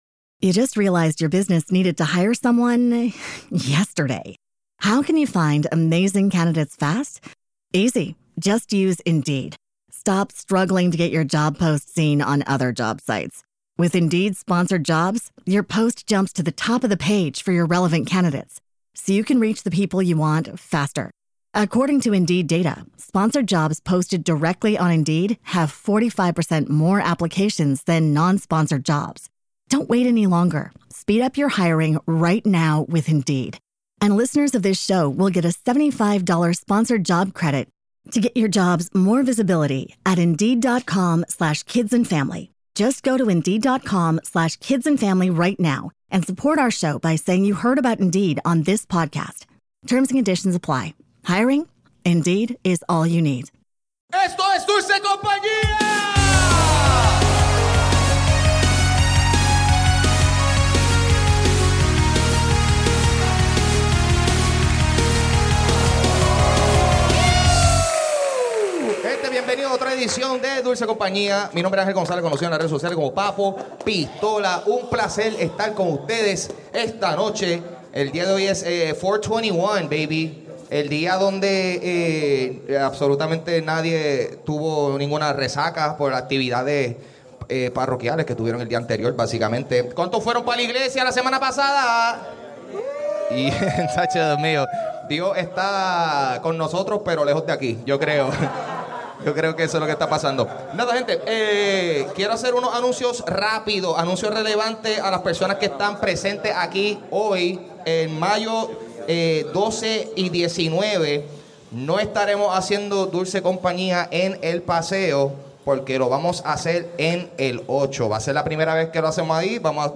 Open Mic | Abril 21, 2025